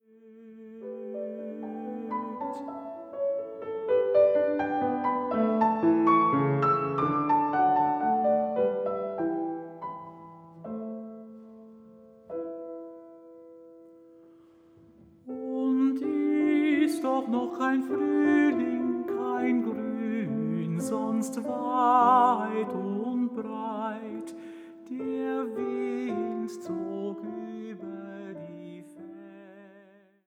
Klavier